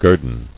[guer·don]